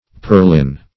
Search Result for " purlin" : The Collaborative International Dictionary of English v.0.48: Purlin \Pur"lin\, Purline \Pur"line\, n. [Etymol. uncertain.]